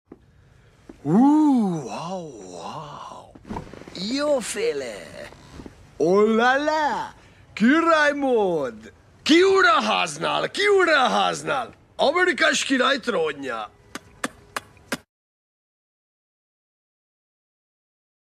CAT 525c Skidder Cold Start🌳🌳 Sound Effects Free Download